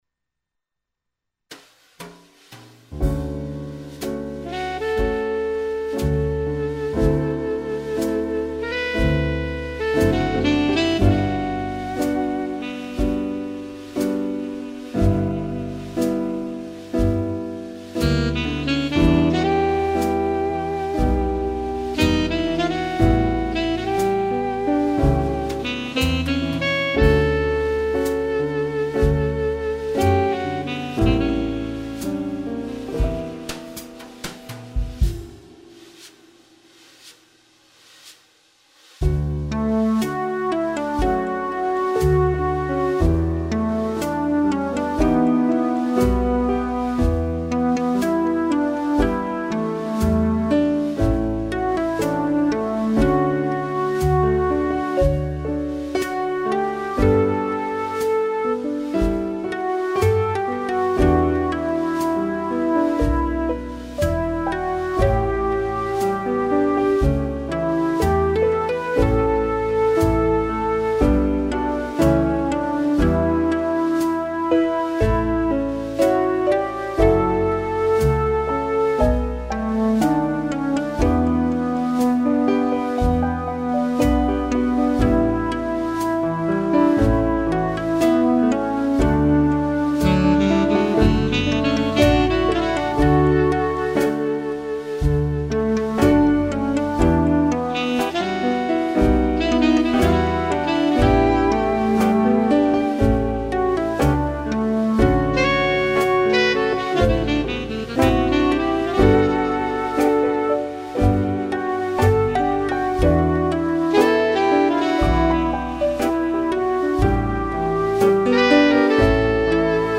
播放音乐版：